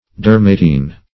dermatine - definition of dermatine - synonyms, pronunciation, spelling from Free Dictionary
Search Result for " dermatine" : The Collaborative International Dictionary of English v.0.48: Dermatic \Der*mat"ic\, Dermatine \Der"ma*tine\, a. [Gr.